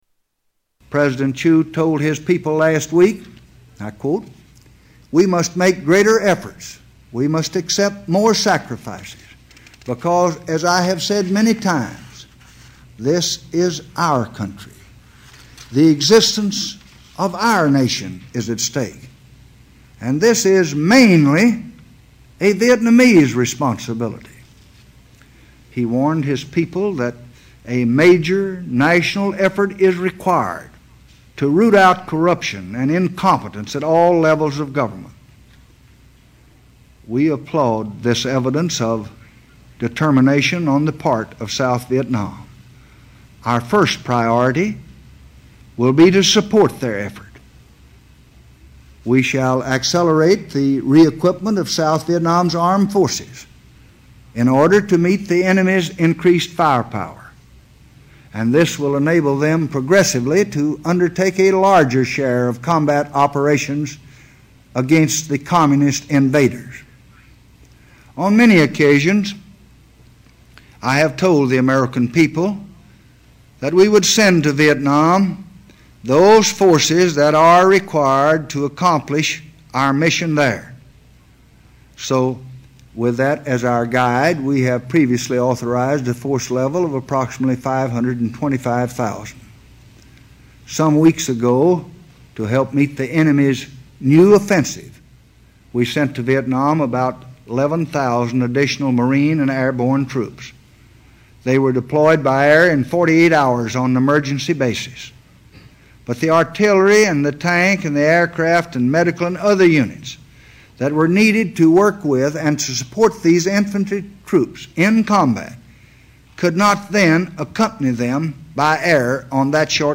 Renunciation Speech Part 3
Tags: Historical Lyndon Baines Johnson Lyndon Baines Johnson clips LBJ Renunciation speech